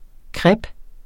Udtale [ ˈkʁab ]